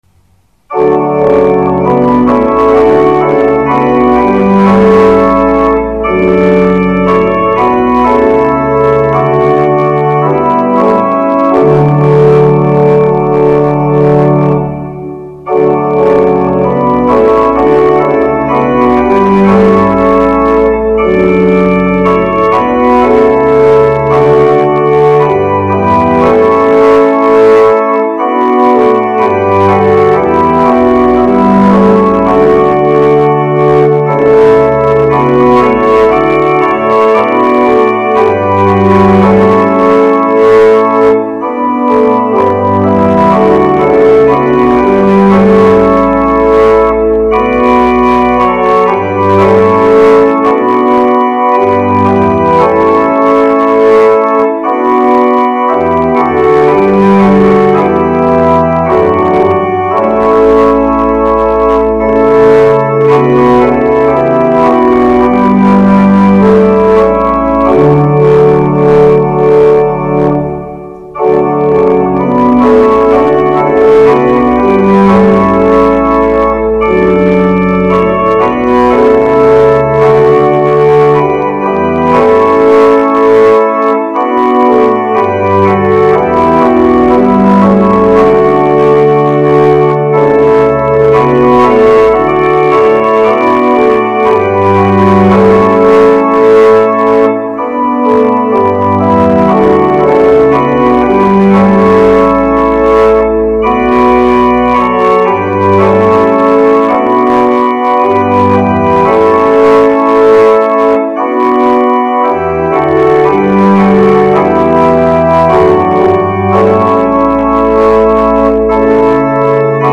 Begeleiding